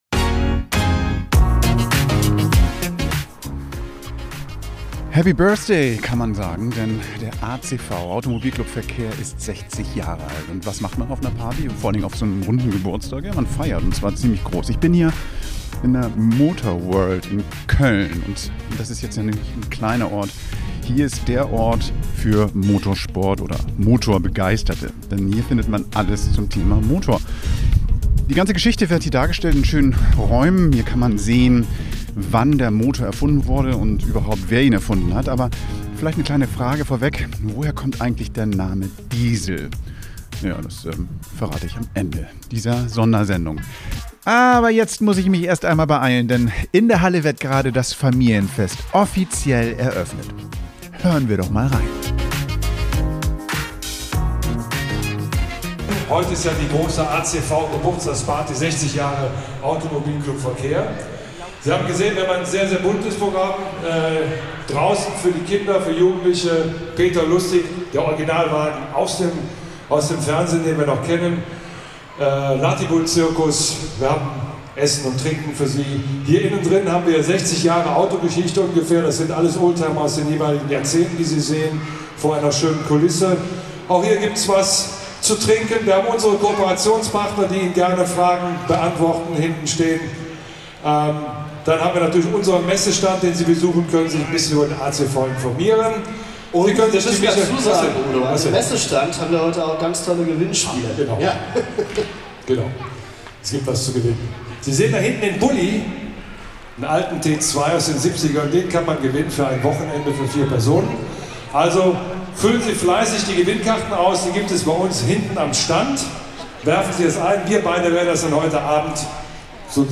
60 Jahre ACV: Live vom Familienfest in der Motorworld ~ By the Way: Das Podcast-Magazin für deine Mobilität Podcast